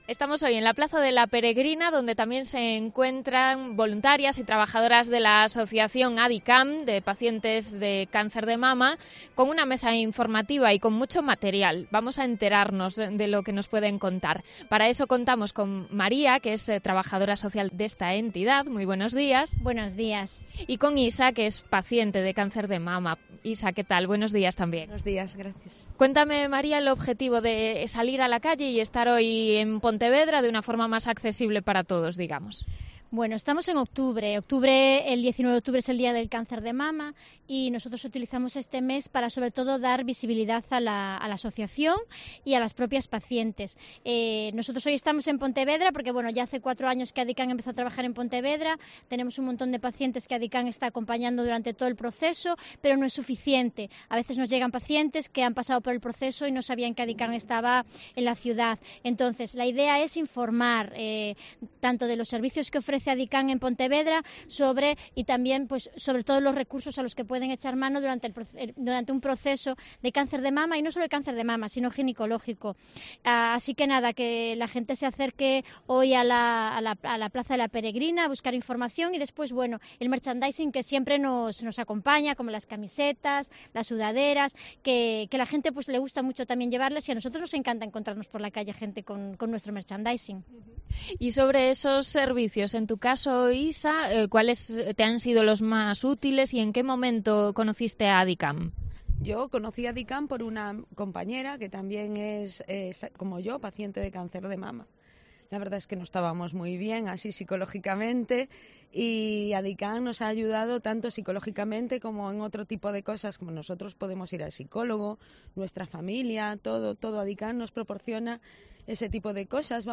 atienden el micrófono de COPE + Pontevedra desde su mesa informativa instalada en la plaza de la Peregrina para explicar los servicios gratuitos y el acompañamiento que Adicam ofrece.